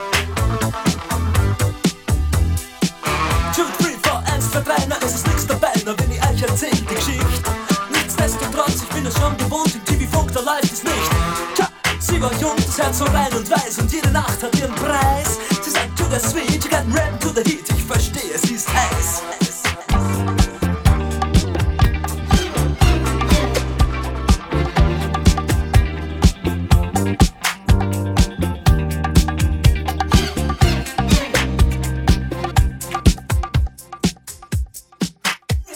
The sound gets sped up really shortly.
At 0:20 ish